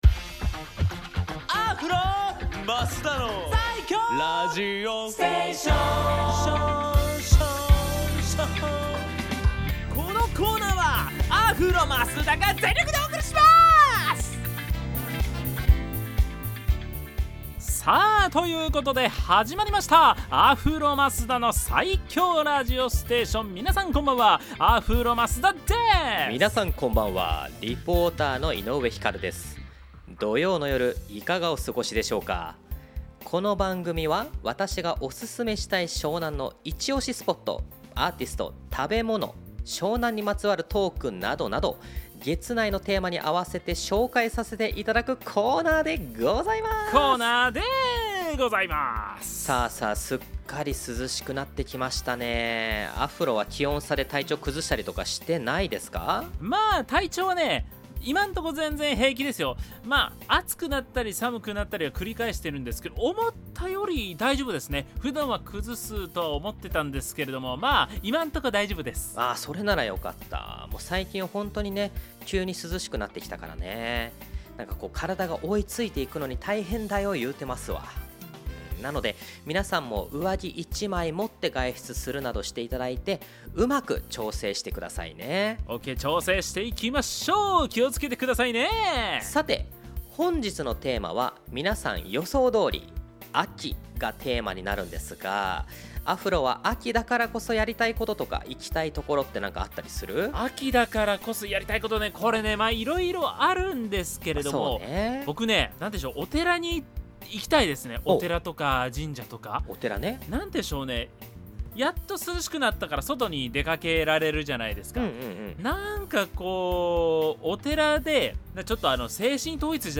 放送音源はこちら